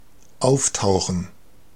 Ääntäminen
US RP : IPA : /əˈpɪə/ GenAm: IPA : /əˈpiɹ/